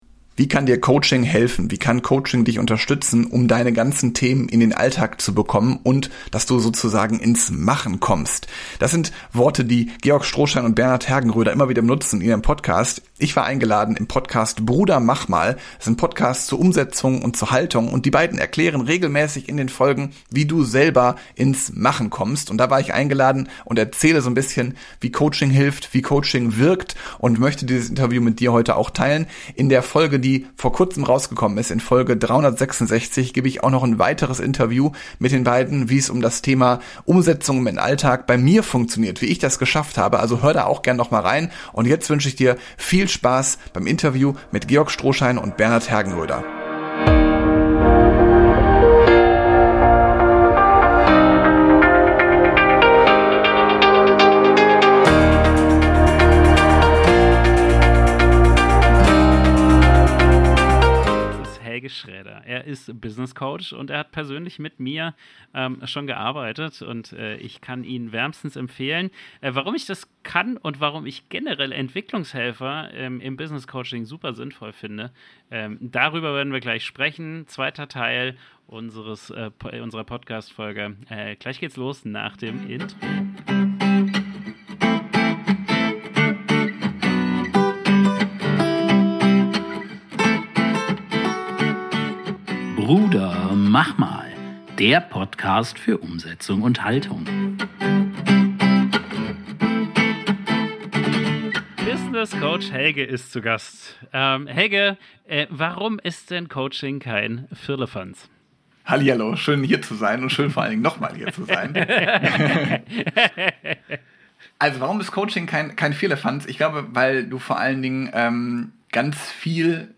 Die Podcast-Folge erschien im Podcast "Bruder, mach mal!". Wir sprechen darüber, wie Coaching wirklich wirkt und warum viele Menschen zwar wissen, was sie tun sollten, aber nie ins Handeln und Umsetzen kommen. Wir sprechen über Veränderungen, Glaubenssätzen, innere Blockaden, Verantwortung, Klarheit und den Punkt, an dem Coaching aus Gedanken endlich Umsetzung macht.